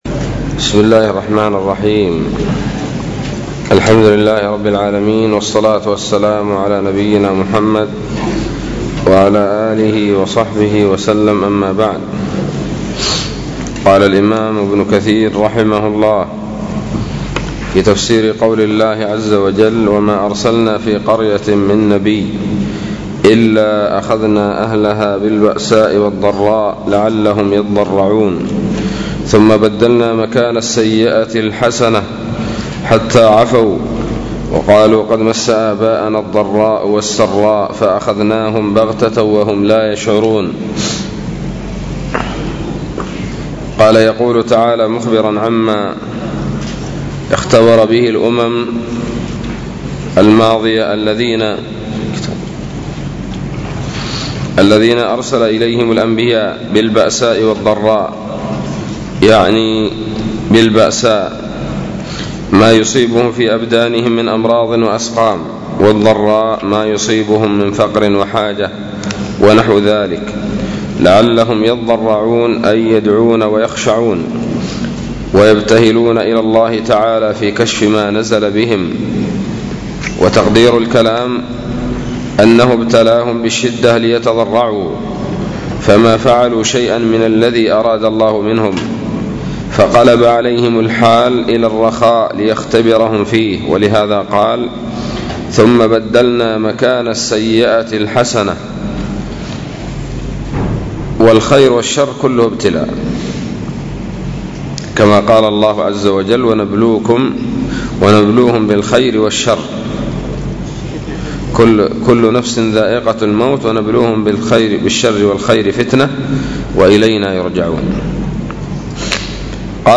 007 سورة الأعراف الدروس العلمية تفسير ابن كثير دروس التفسير